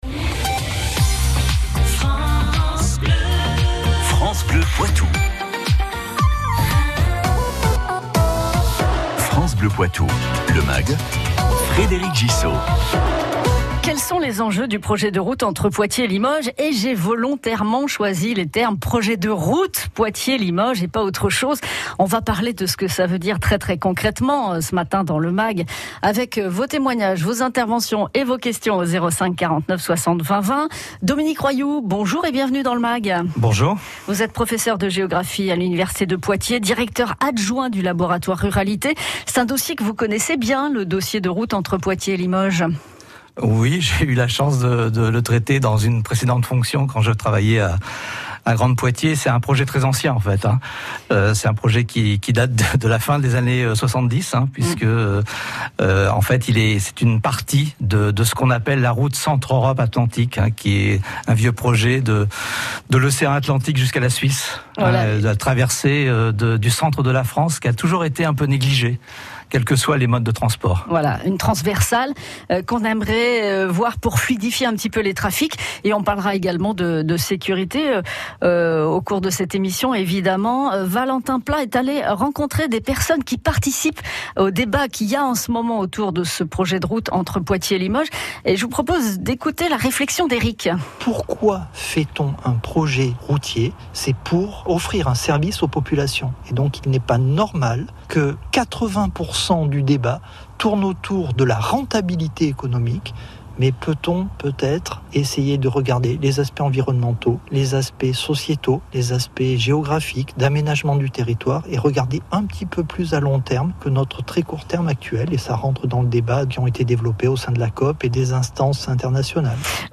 intervention de DÉCAPE à 04:50